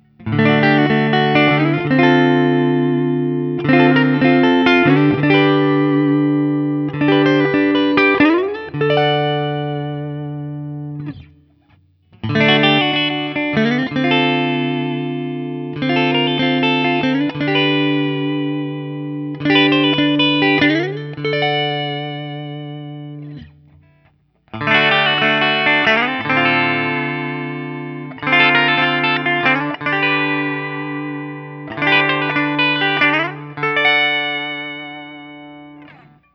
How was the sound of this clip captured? I’ve had this guitar for some time and I had made the recordings using my Axe-FX II XL+ setup through the QSC K12 speaker recorded direct into my Macbook Pro using Audacity. For the first few recordings I cycled through the neck pickup, both pickups, and finally the bridge pickup.